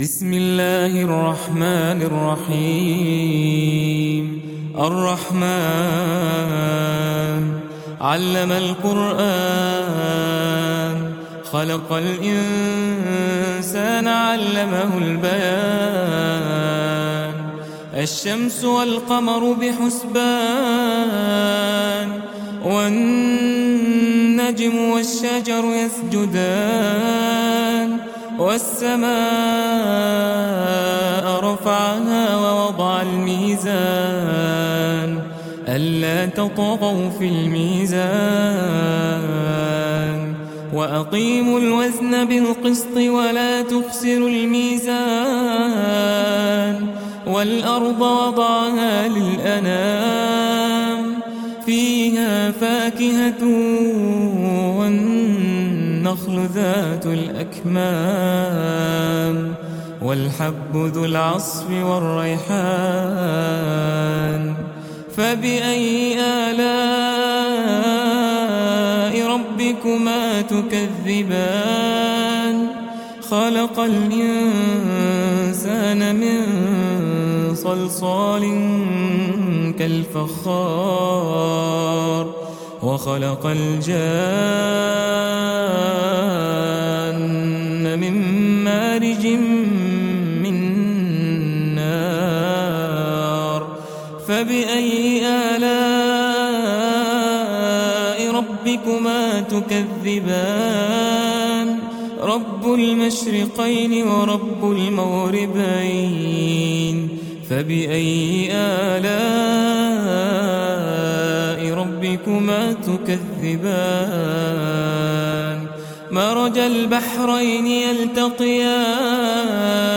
سورة الرحمن (مقام الكرد) - إندونيسي